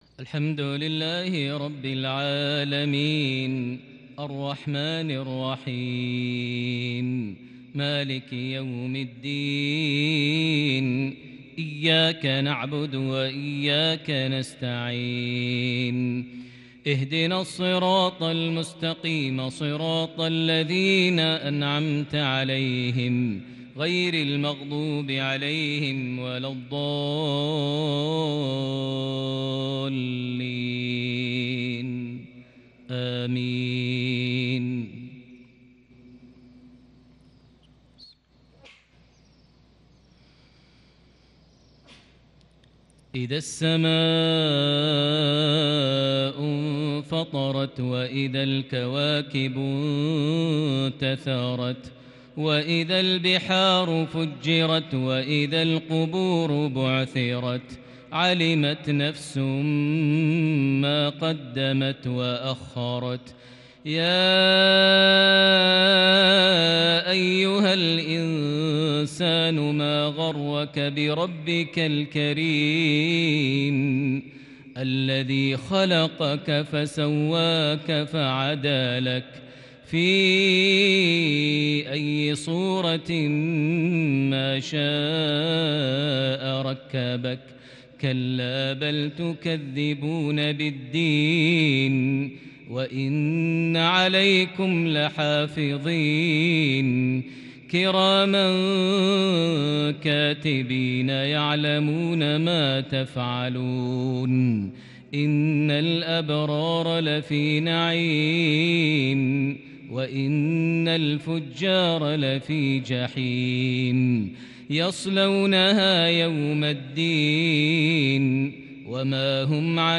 تلاوة كردية بديعة لسورتي الإنفطار - الطارق | مغرب 29 صفر 1442هـ > 1442 هـ > الفروض - تلاوات ماهر المعيقلي